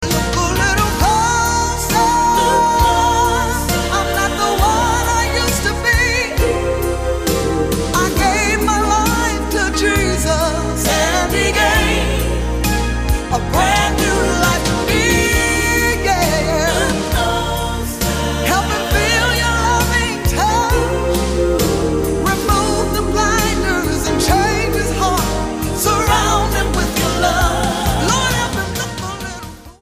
STYLE: Gospel
The inspirational ballad title track is a standout.